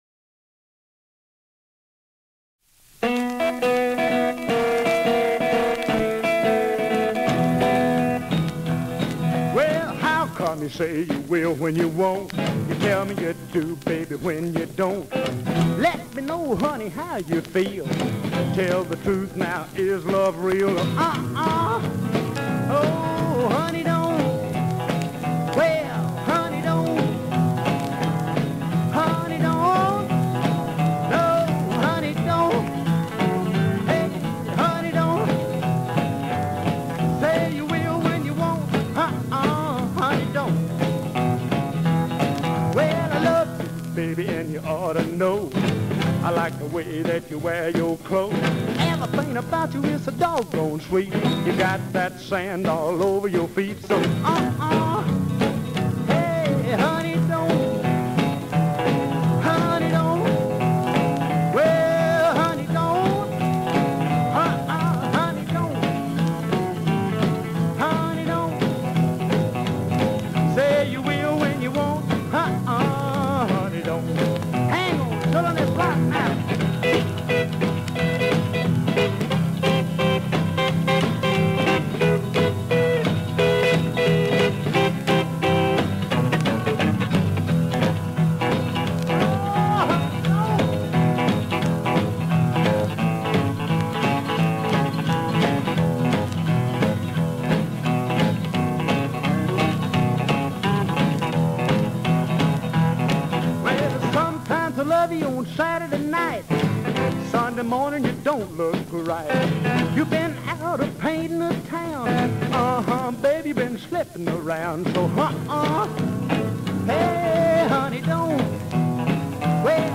Broadcast from the Hudson studio.